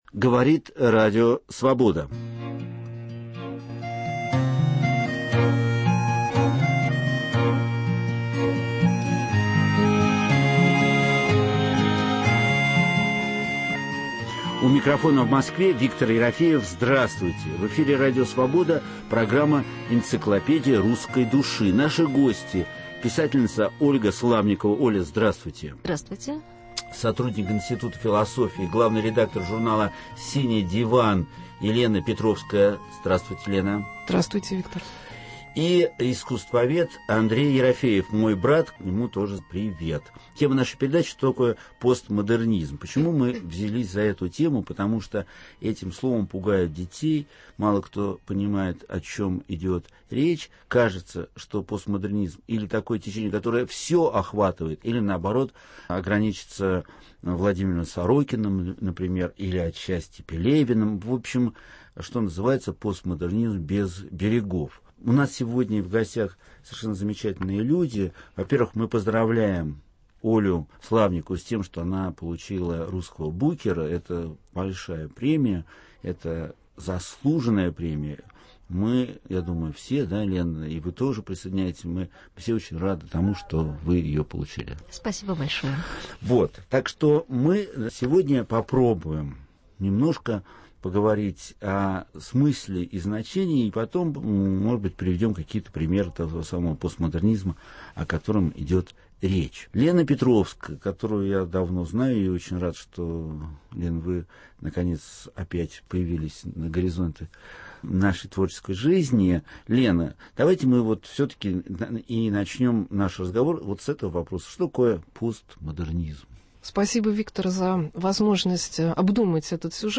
Что такое постмодернизм? Гости – писательница Ольга Славникова